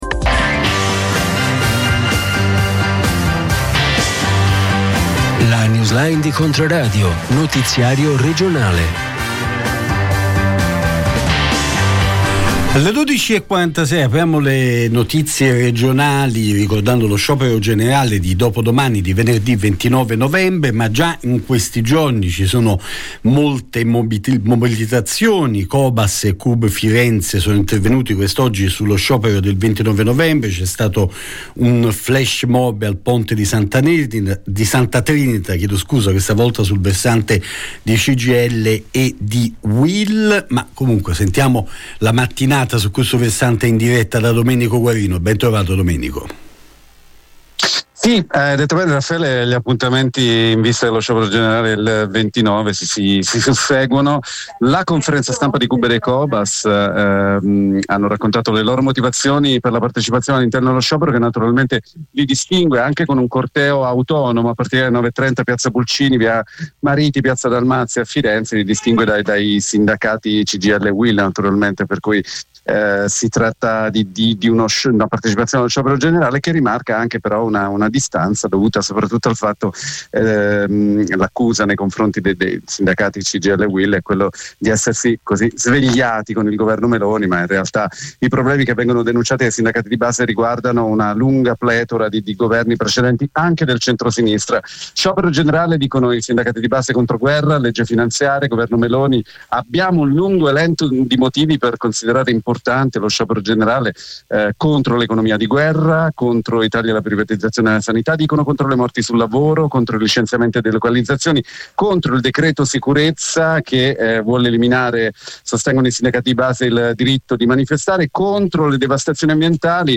Notiziario regionale